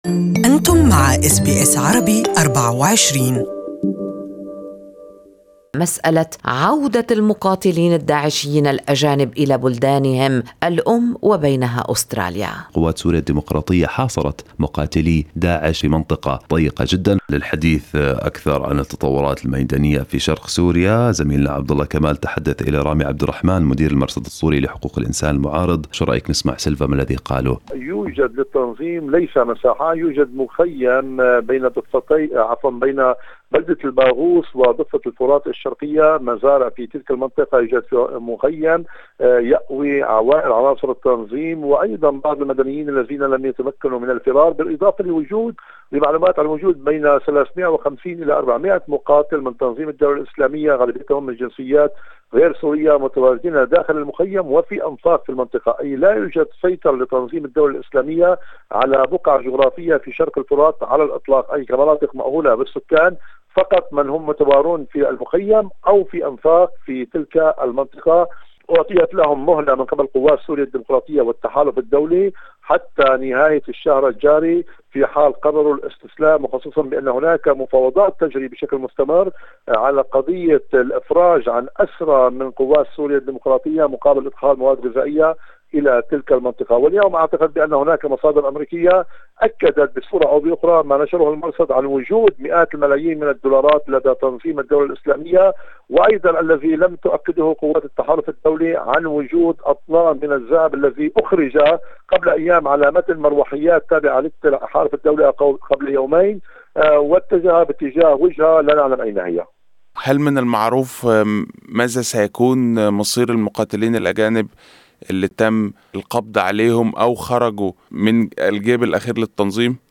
استمعوا للتقرير الصوتي المرفق بالصورة أعلاه.